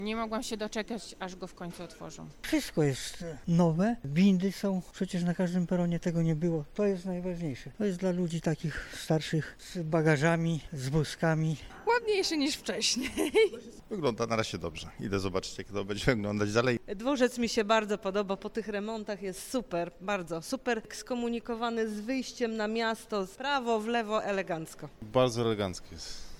Zapytaliśmy pasażerów, jak oceniają zmiany.